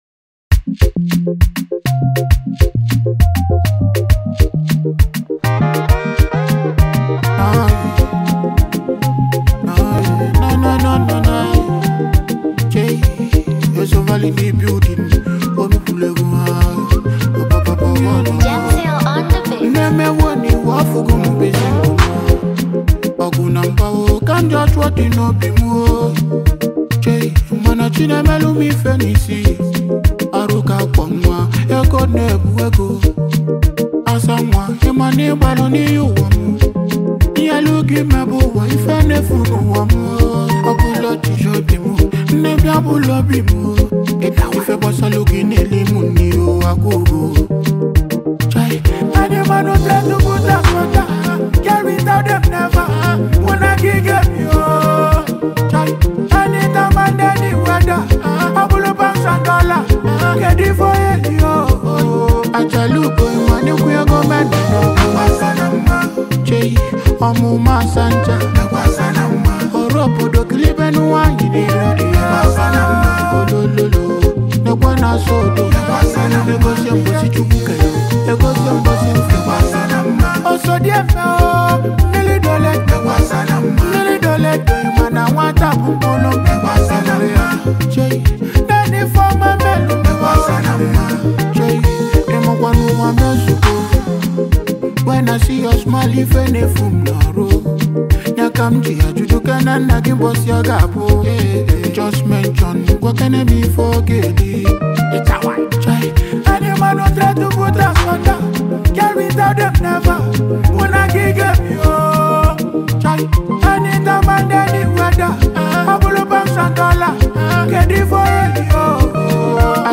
Highlife Music
highlife